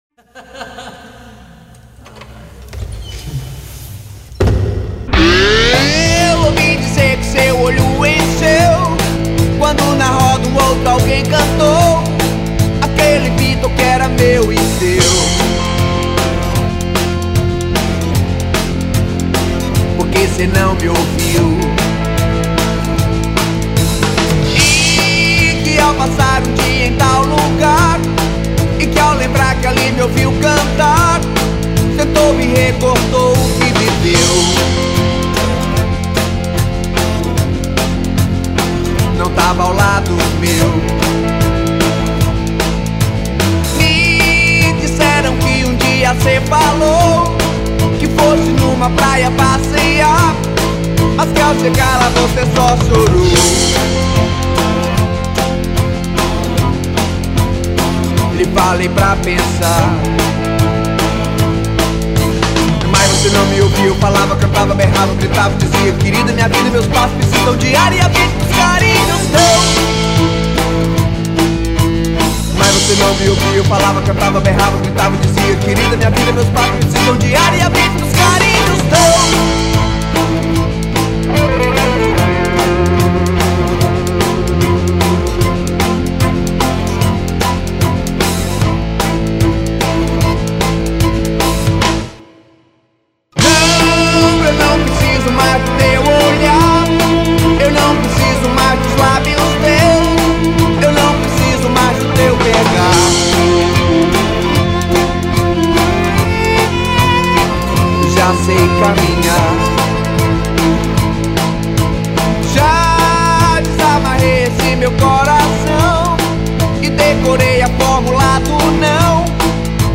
1978   03:14:00   Faixa:     Rock Nacional